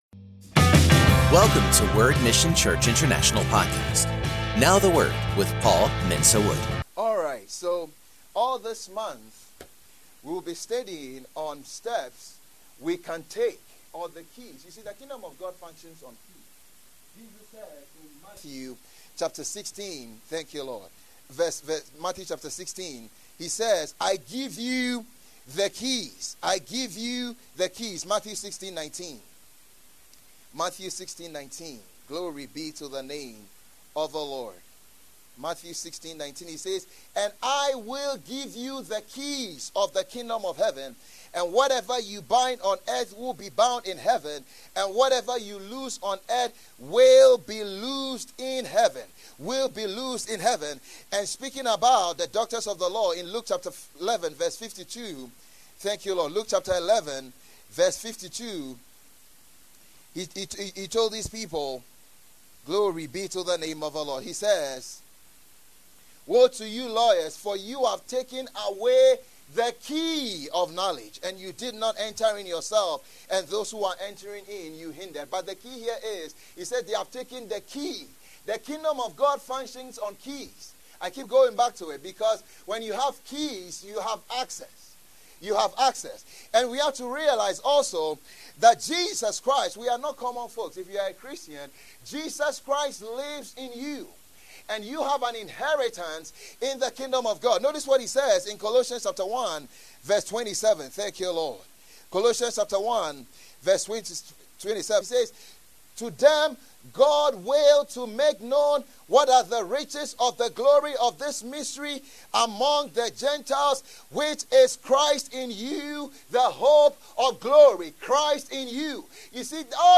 Post category: Sermon